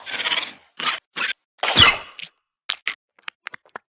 Sfx_creature_babypenguin_hold_sneeze_above_01.ogg